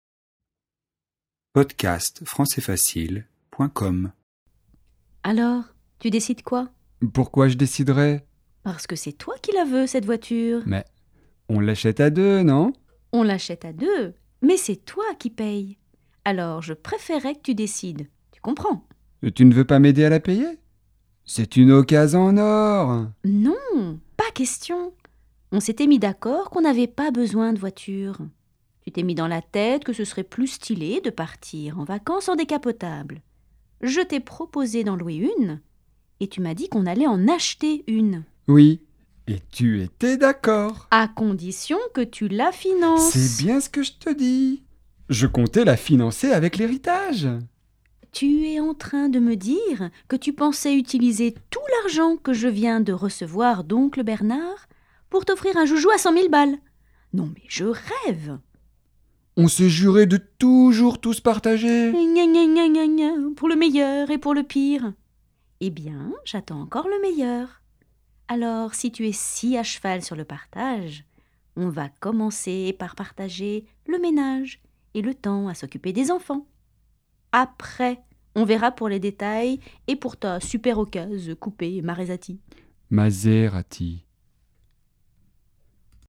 Dialogue pour étudier la grammaire :
Registre : courant - soutenu